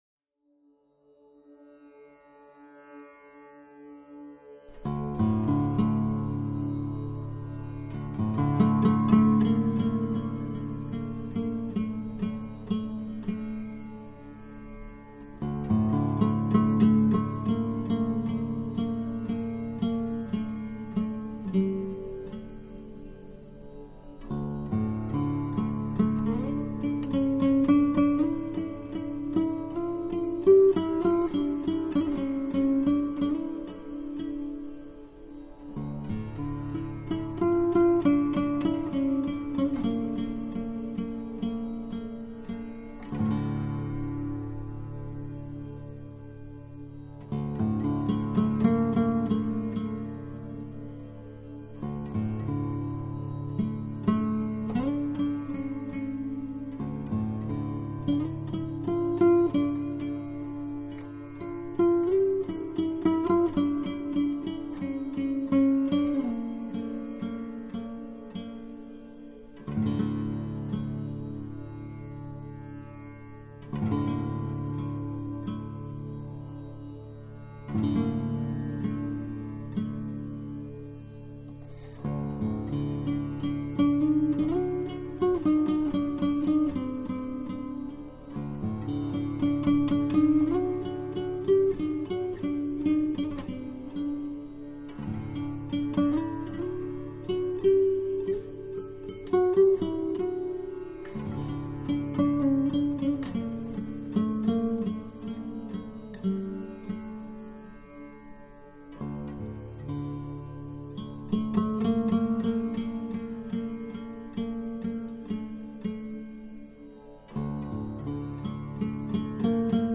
Genre: World/New Age